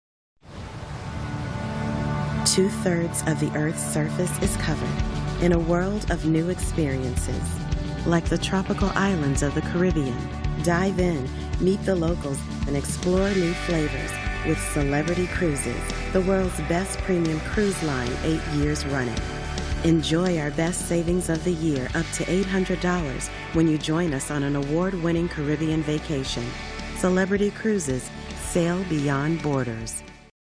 Inglés (Americano)
Llamativo, Seguro, Natural, Suave, Empresarial
Audioguía
She works from an amazing home studio with professional equipment.